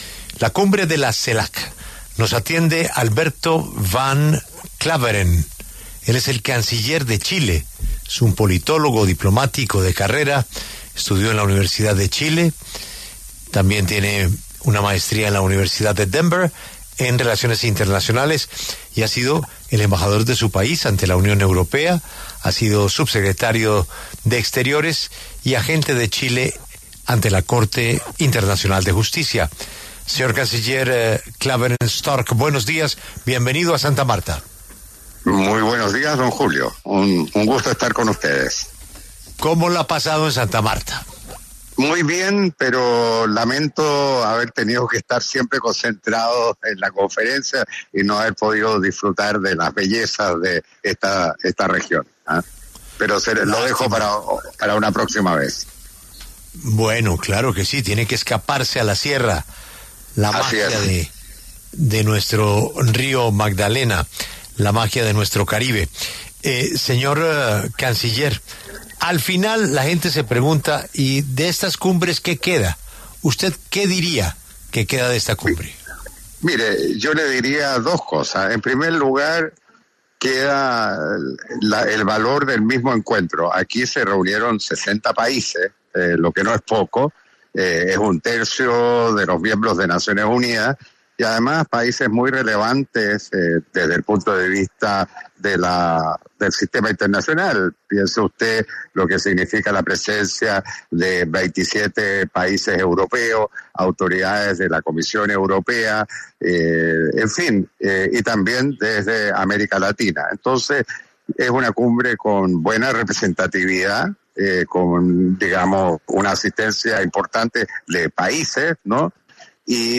El canciller chileno Alberto van Klaveren Stork conversó con La W sobre el desarrollo de la Cumbre Celac-UE que se lleva a cabo desde Santa Marta.
Alberto van Klaveren Stork, canciller de Chile, conversó con La W a propósito de la IV Cumbre Celac- Unión Europea que se realiza en la ciudad de Santamarta del 9 al 10 de noviembre.